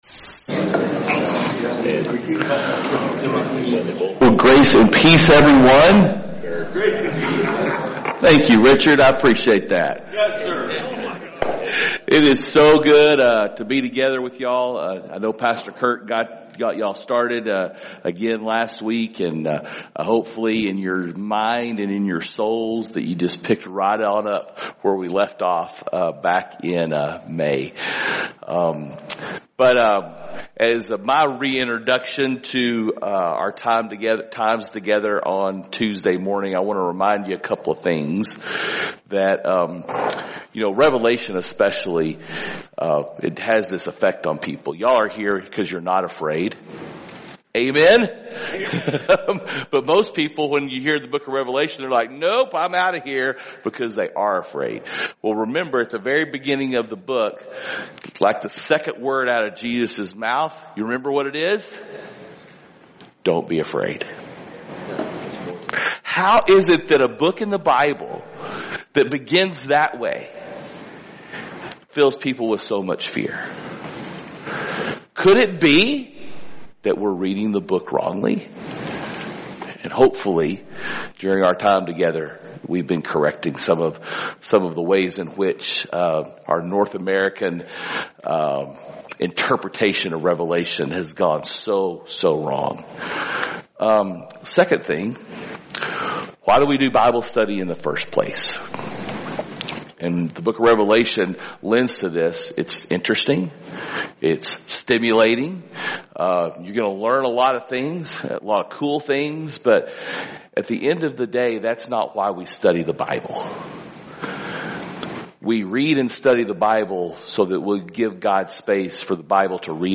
Men’s Breakfast Bible Study 8/20/24